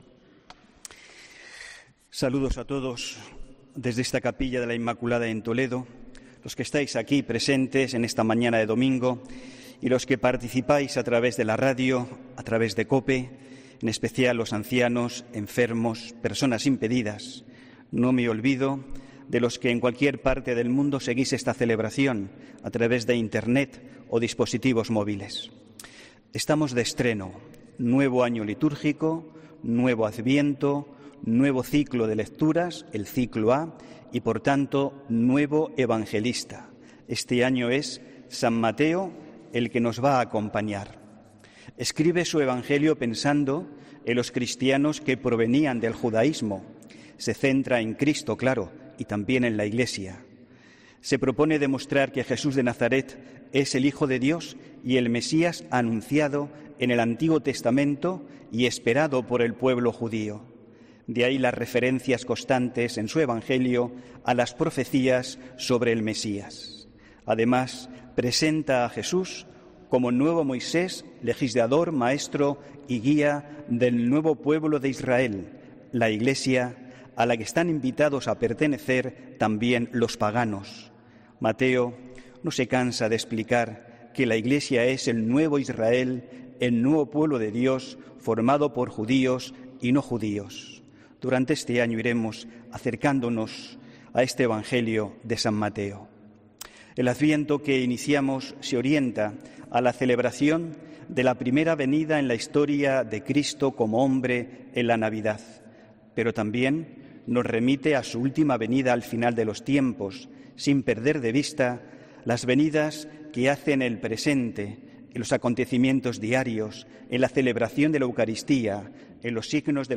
HOMILÍA 1 DICIEMBRE
AUDIO: HOMILÍA 1 DICIEMBRE